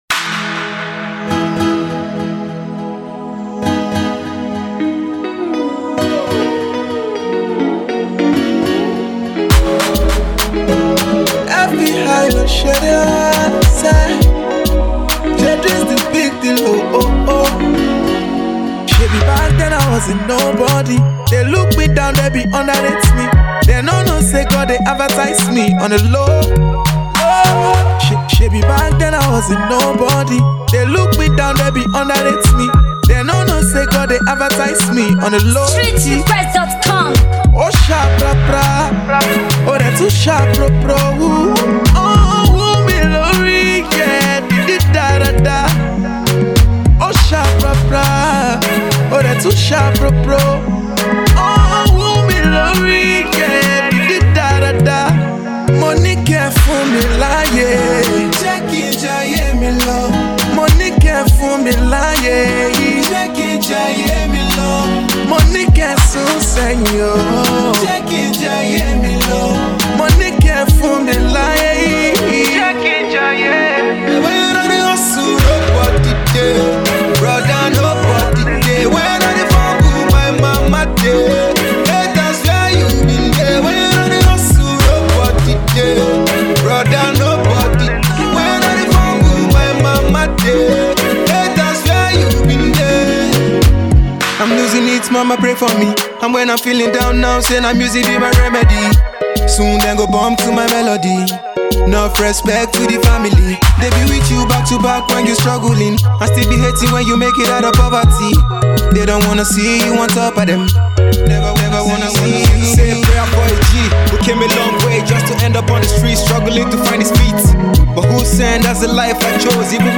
motivational song